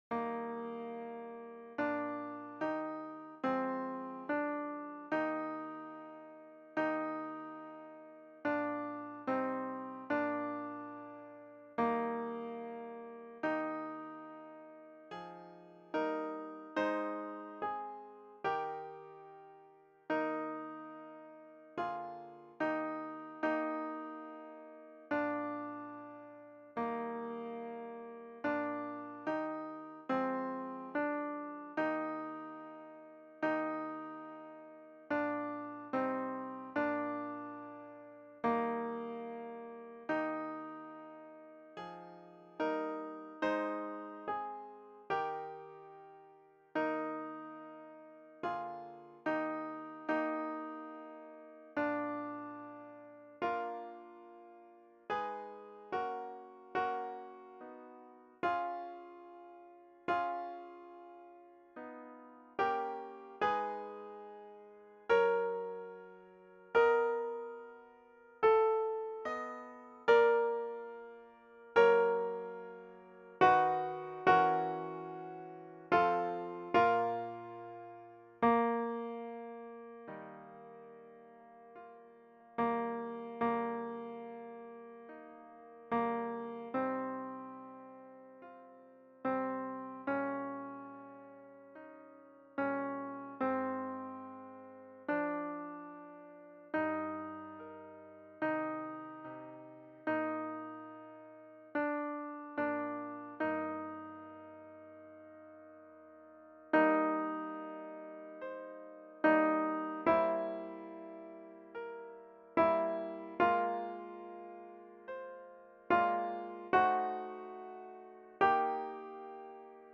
MJrmEg0WTIF_Ave-verum-St-Saëns---mp3-Alto-+-3-voix.mp3